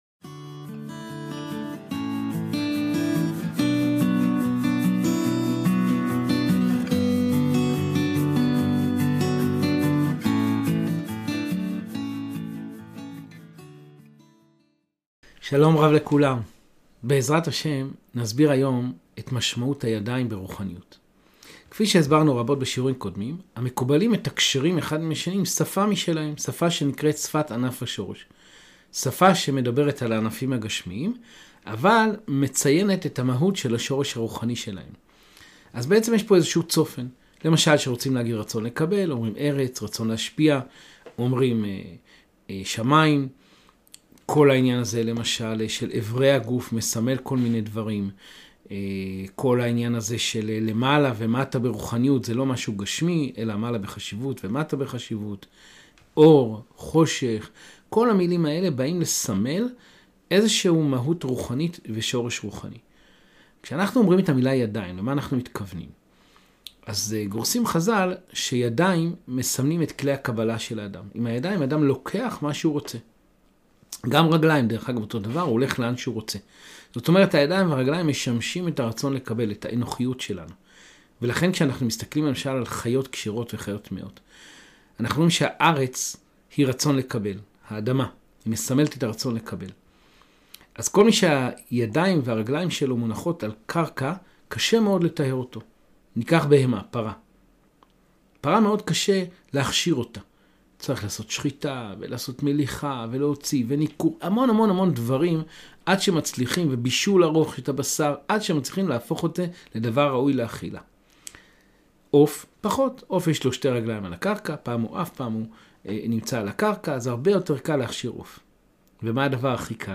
ידיים רוחניות | שיעור קבלה ברוח חסידות אשלג